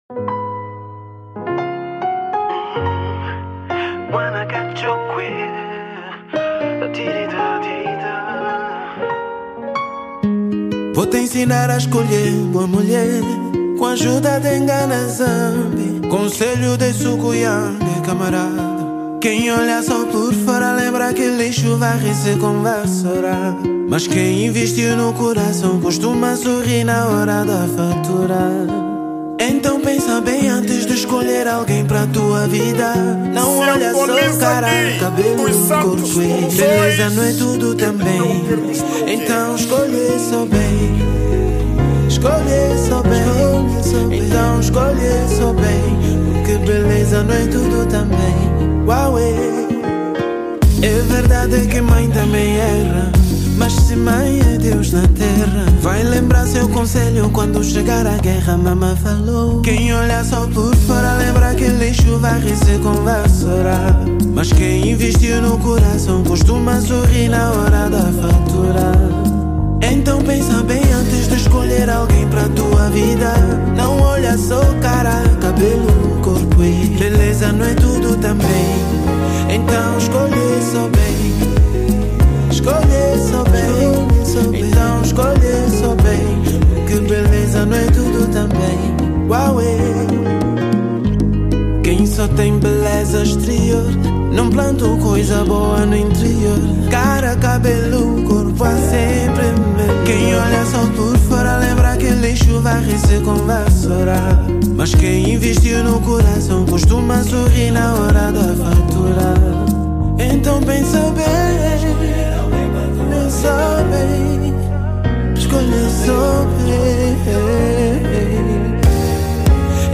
Categoria:  Zouk